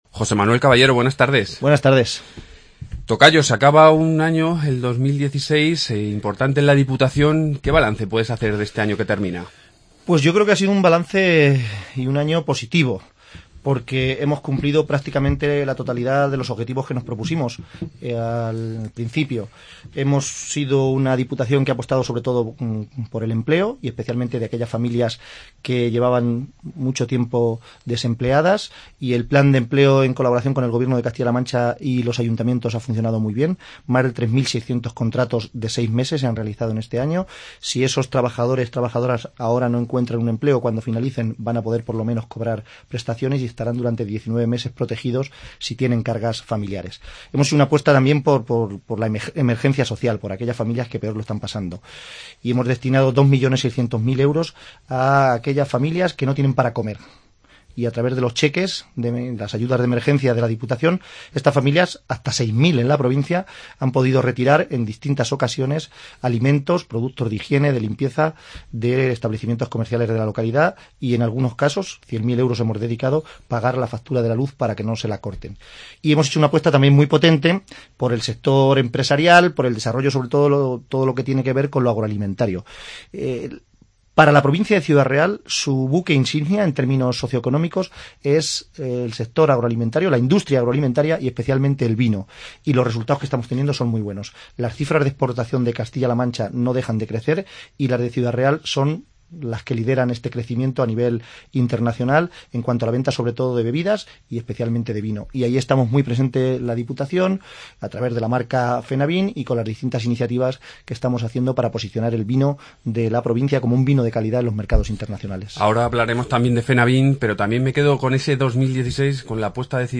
Entrevista presidente Diputación-José Manuel Caballero